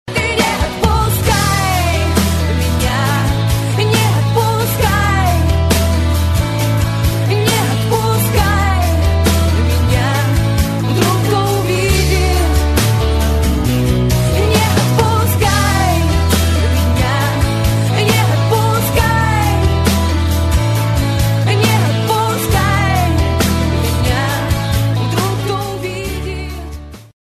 • Качество: 64, Stereo
поп-рок